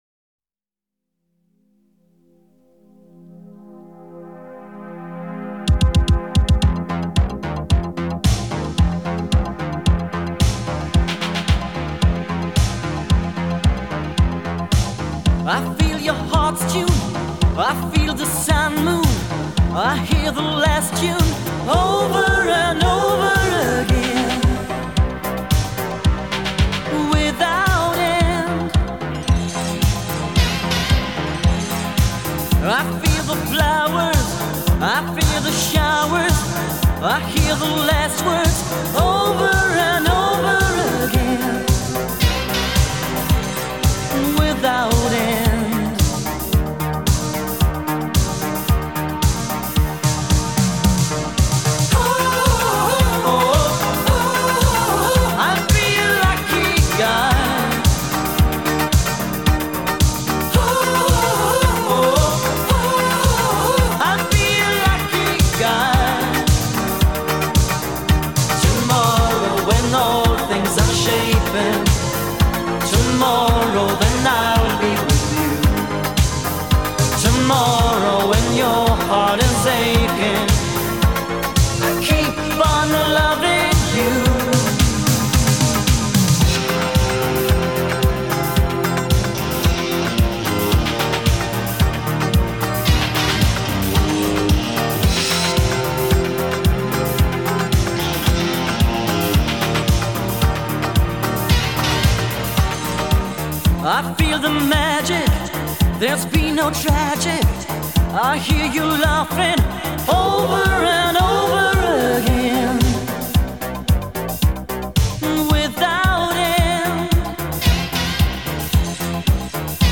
پاپ آلمانی